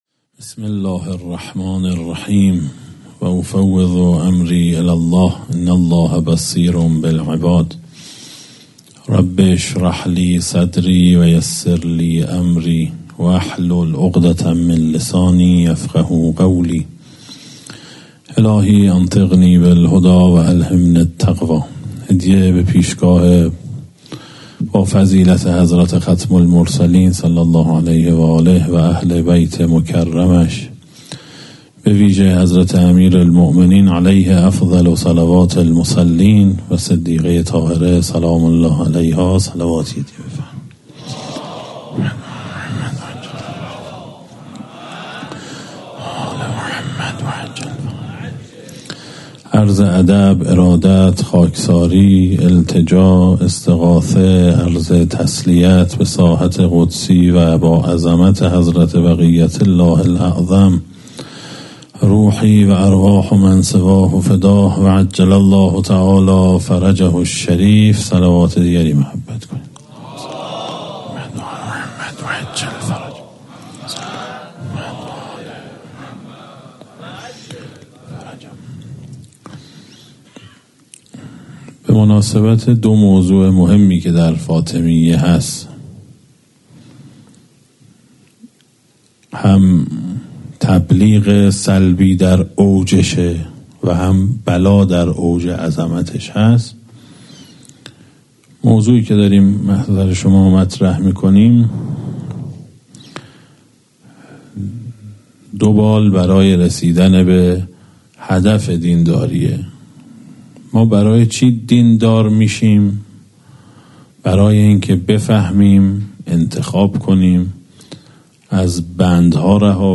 در هیئت محترم عبدالله بن الحسن علیهما السلام تهران
سخنرانی